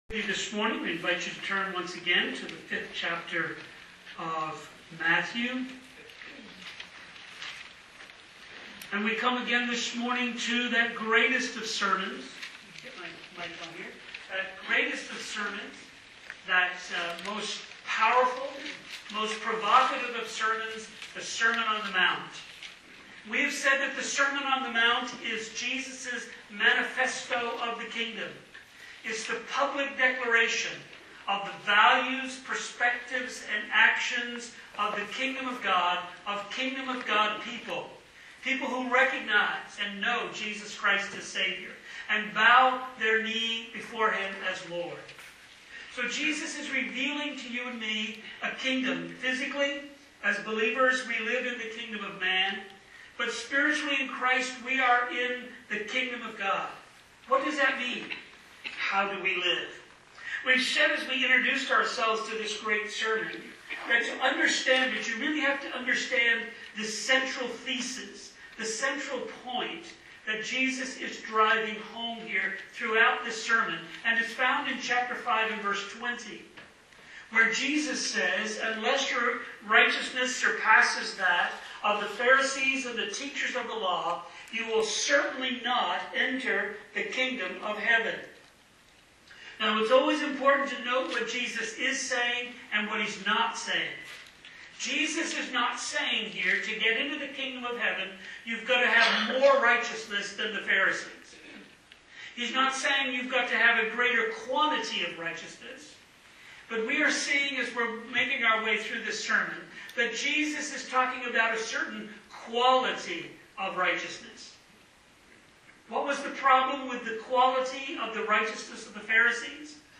Am-I-an-Adulterer-Sermon-on-the-Mount.mp3